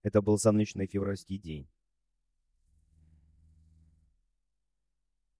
TTS Example_ это был солнечный февральский день.mp3